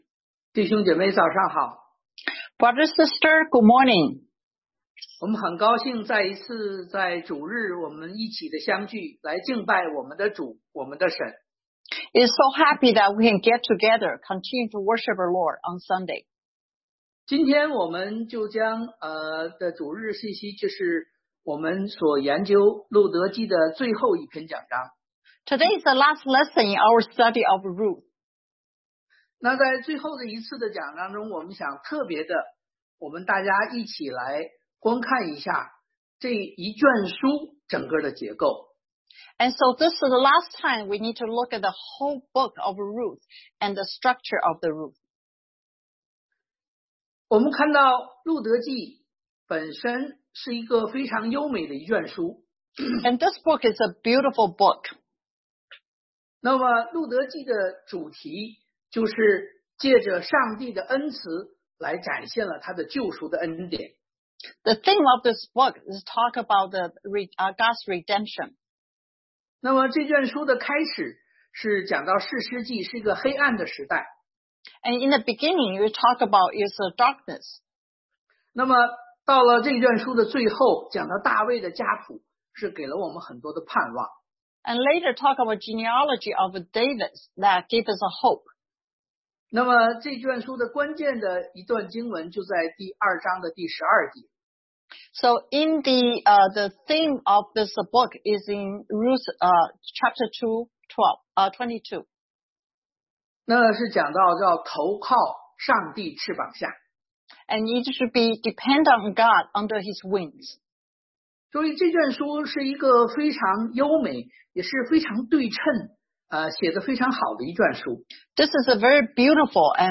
Ruth 4:13-22 Service Type: Sunday AM Bible Text